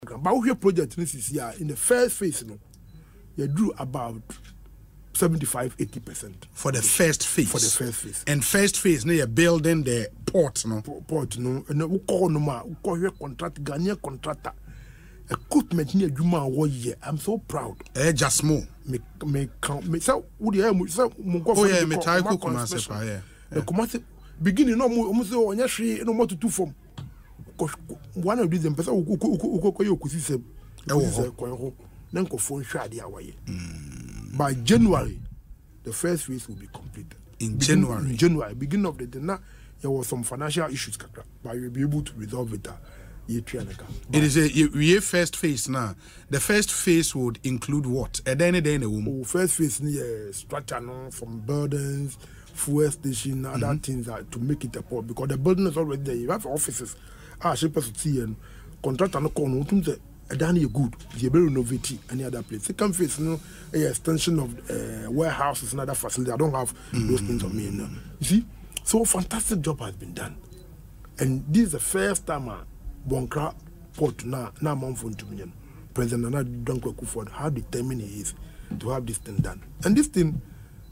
Speaking on Asempa FM’s Ekosii Sen show, Minister for Transport, Kwaku Ofori Asiamah, disclosed that the first phase of the project is about 75% to 80% complete.